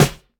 Focused Steel Snare Drum Sound C# Key 02.wav
Royality free steel snare drum sound tuned to the C# note. Loudest frequency: 2108Hz
focused-steel-snare-drum-sound-c-sharp-key-02-aTO.ogg